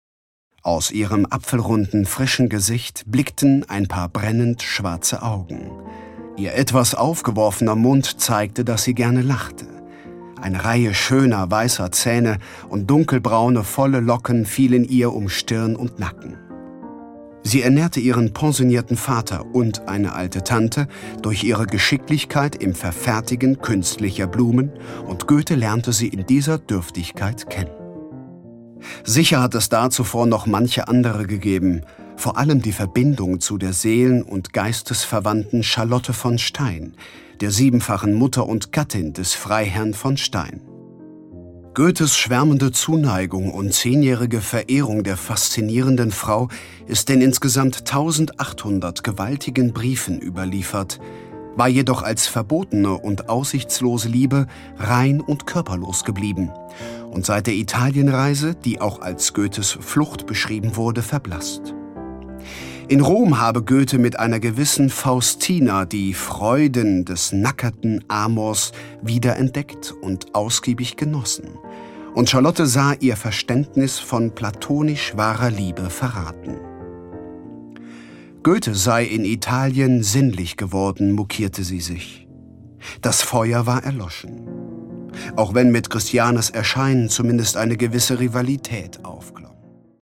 Briefe einer außergewöhnlichen Liebe - Christiane Vulpius - Hörbuch